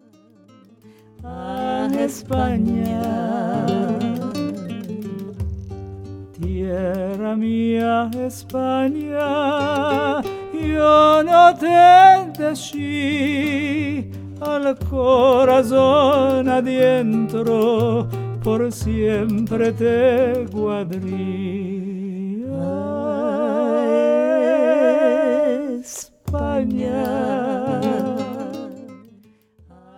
A vibrant, alive collection of Ladino duets
Folk